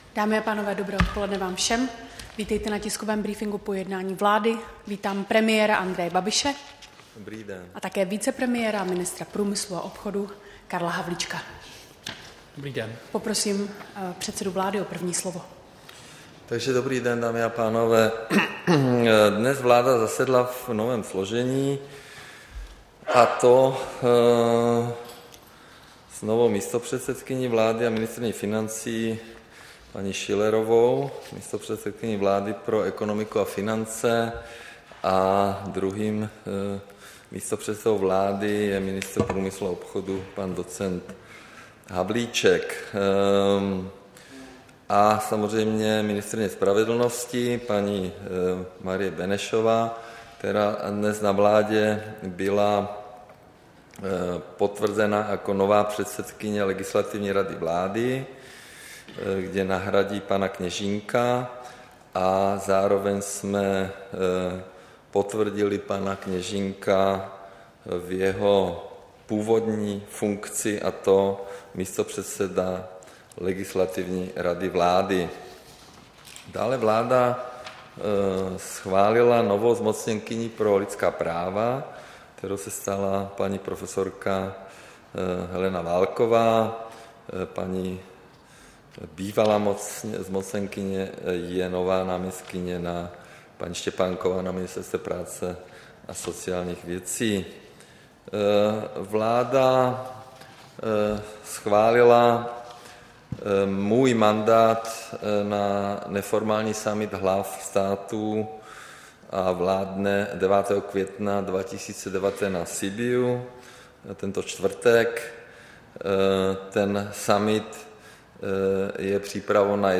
Tisková konference po jednání vlády, 6. května 2019